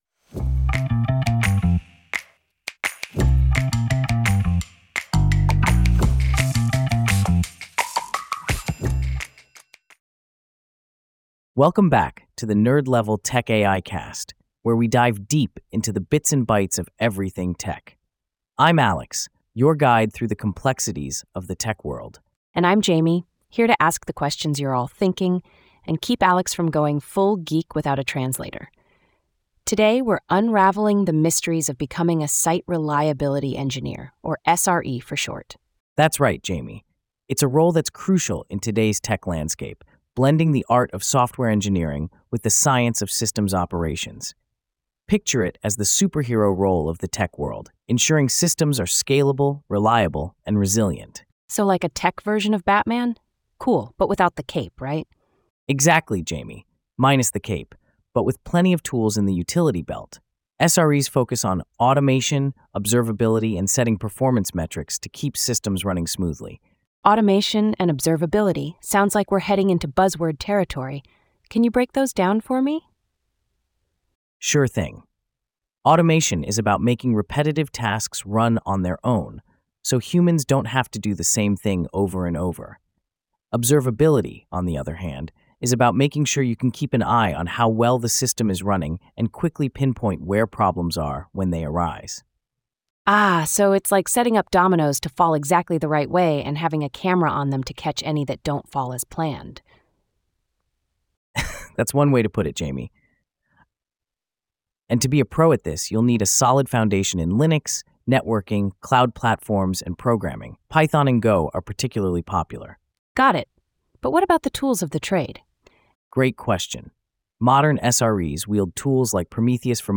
AI-generated discussion by Alex and Jamie